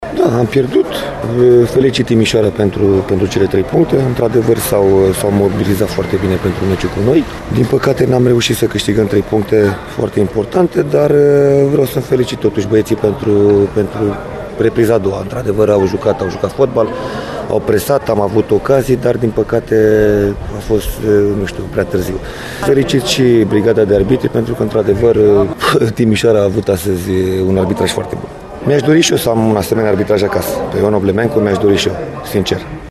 La declarațiile de după meci